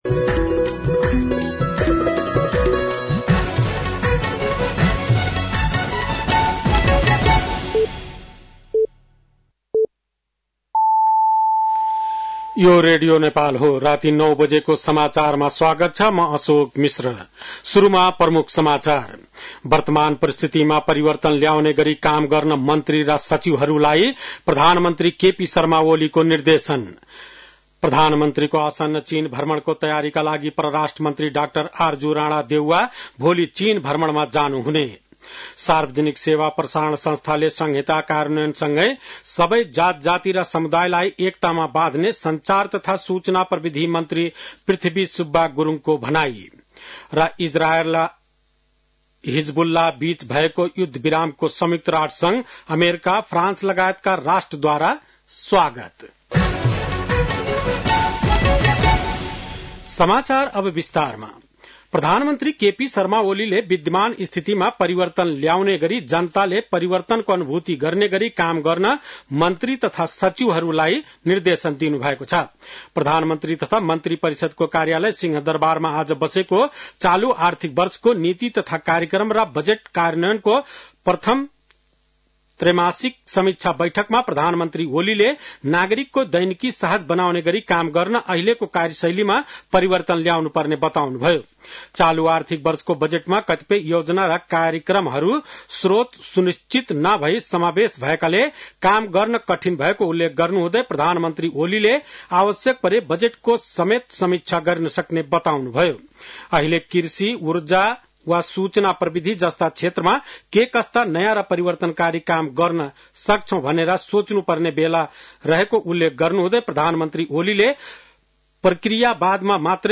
बेलुकी ९ बजेको नेपाली समाचार : १३ मंसिर , २०८१
9-PM-Nepali-NEWS-8-12.mp3